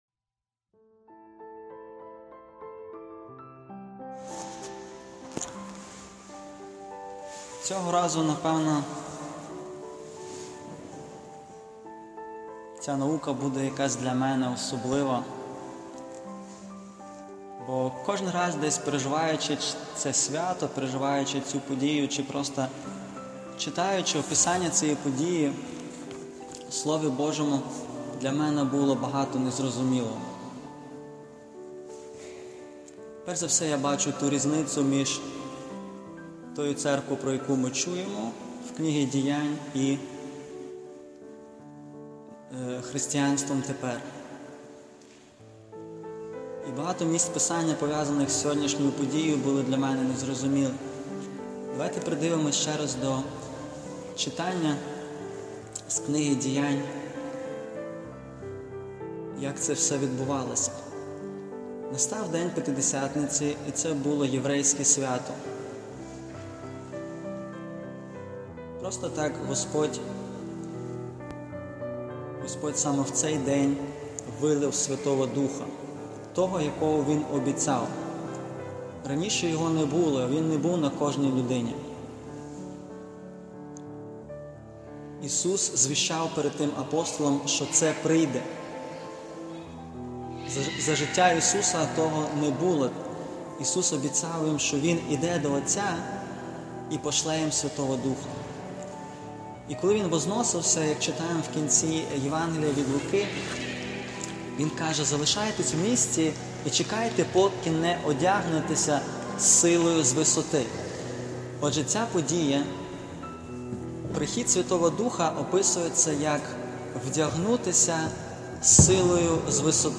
Проповіді